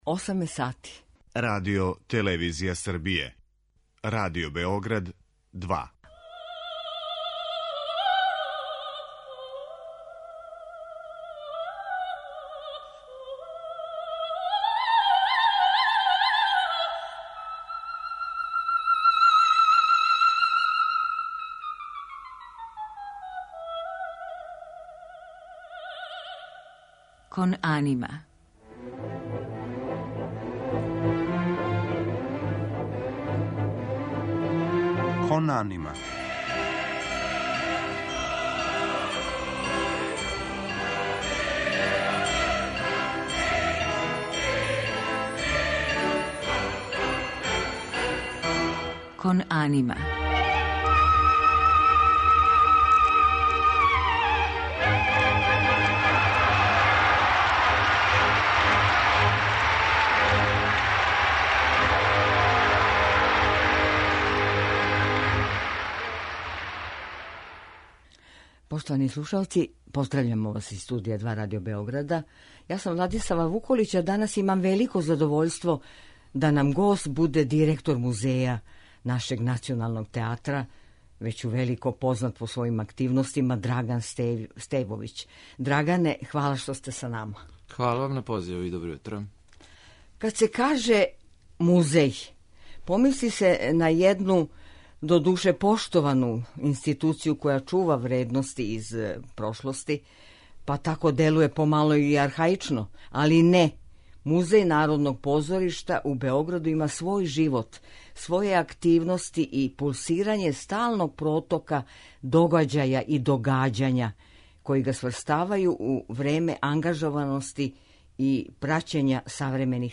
У музичком делу биће емитоване арије у извођењу неких наших најпознатијих вокалних уметника из прошлих дана.